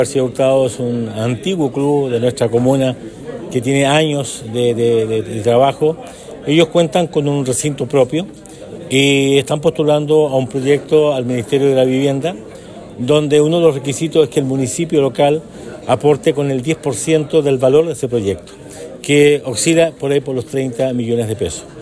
Según explicó el Alcalde Emeterio Carrillo, este proyecto fue gestionado a través del Ministerio de Vivienda y Urbanismo, que establece como requisito para su aprobación un aporte del 10% del total del financiamiento por parte del municipio.